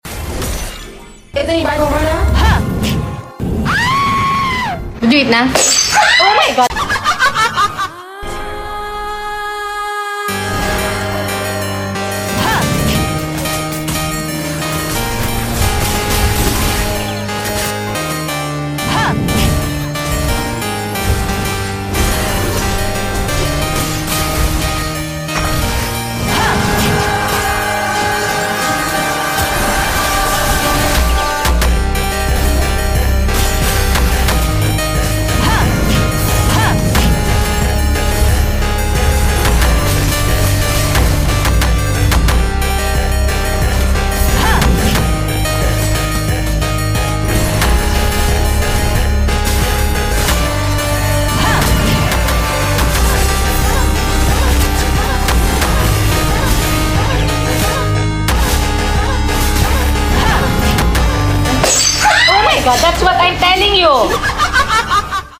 New Selena Zenith Skill Sound sound effects free download